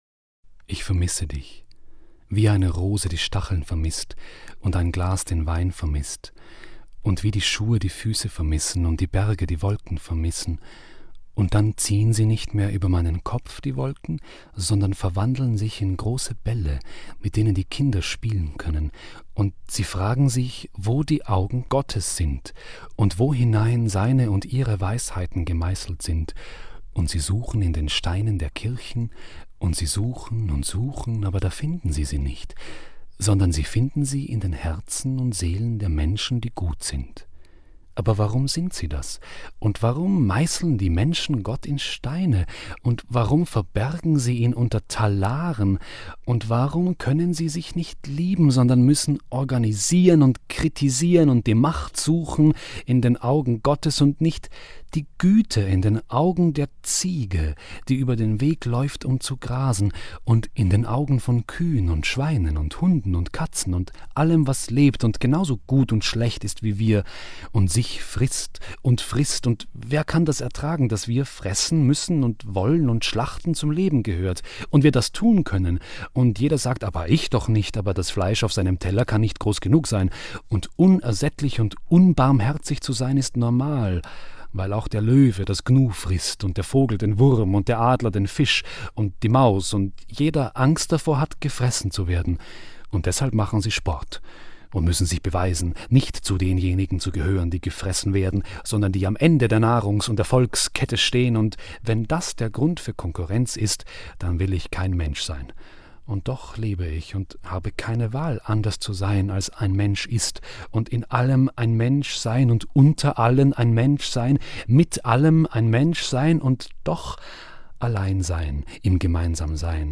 Im Himmel gebadetes Obst Doppel-CD Hörbuch